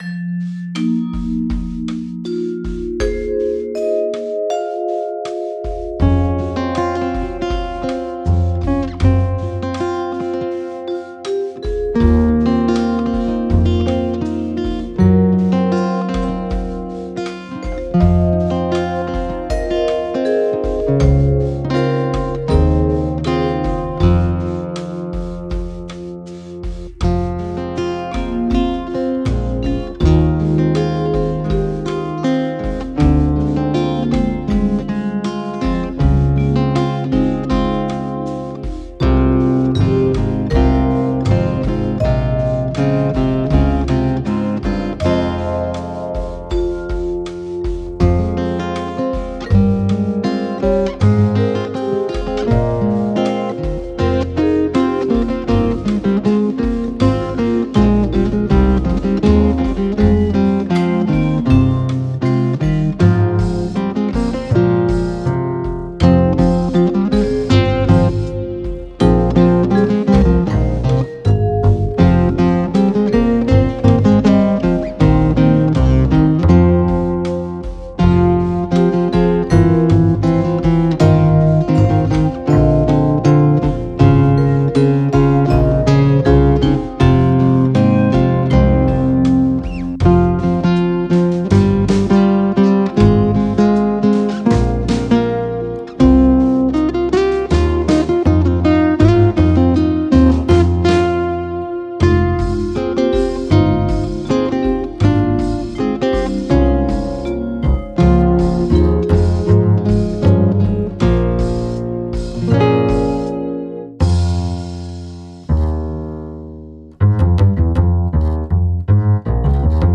Alle Stücke auf dieser Seite liegen in CD-Qualität vor.